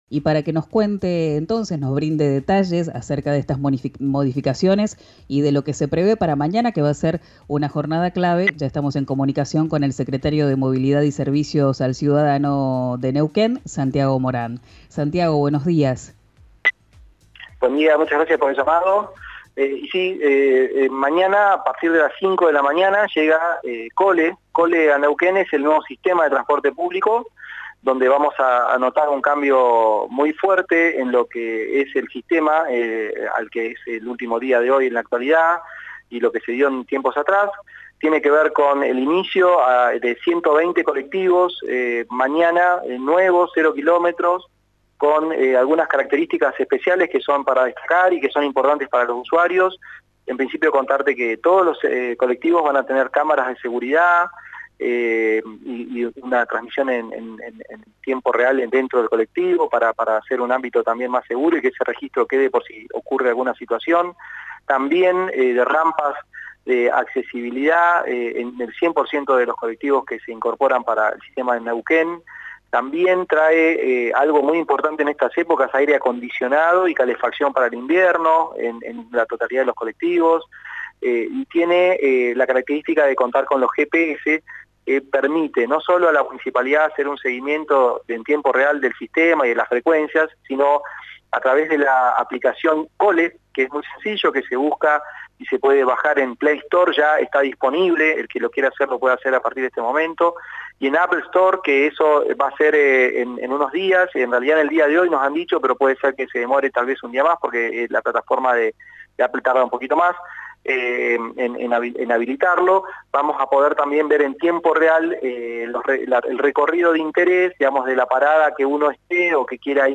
Santiago Morán, el secretario de Movilidad y Servicios al Ciudadano, aseguró que será 'un cambio muy fuerte' para los usuarios. Escuchá la entrevista en «Quien dijo verano», por RÍO NEGRO RADIO.